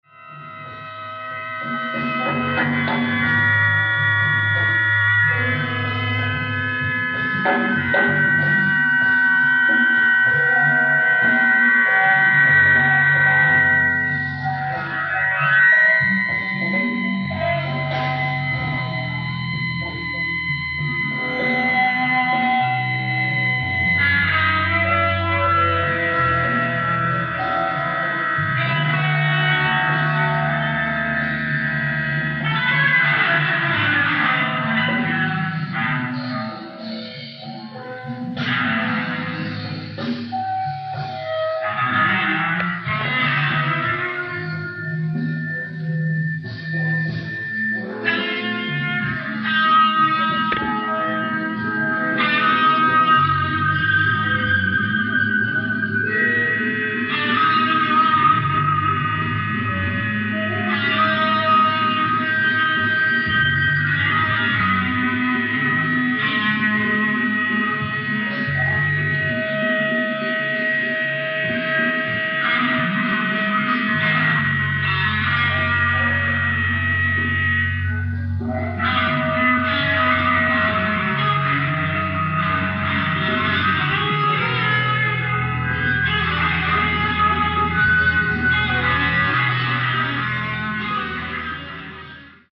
ライブ・アット・ザ・プレイハウス、ホフストラ大学、ヘンプステッド、ニューヨーク 03/22/1975
海外マニアによるリマスター音源！！
※試聴用に実際より音質を落としています。